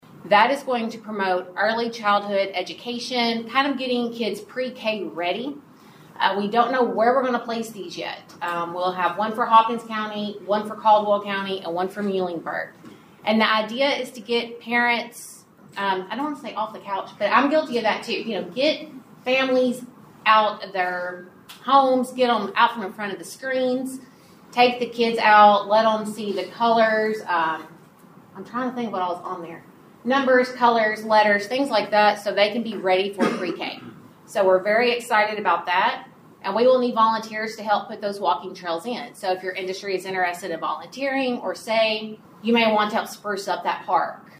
The United Way of the Coalfield Award Celebration took place at the Steve Beshear Center for Post-Secondary Education on the Madisonville Community College campus.